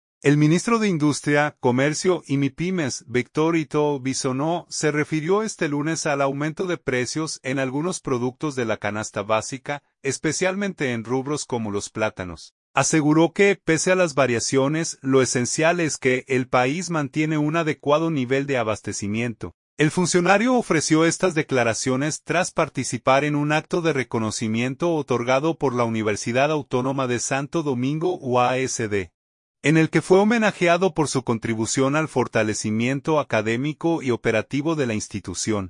El funcionario ofreció estas declaraciones tras participar en un acto de reconocimiento otorgado por la Universidad Autónoma de Santo Domingo (UASD), en el que fue homenajeado por su contribución al fortalecimiento académico y operativo de la institución.